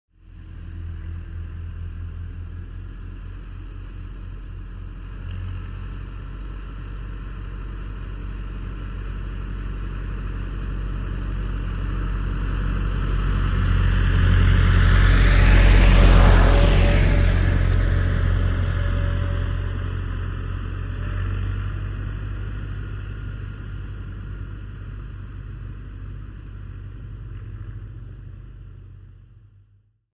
دانلود صدای تراکتور 1 از ساعد نیوز با لینک مستقیم و کیفیت بالا
جلوه های صوتی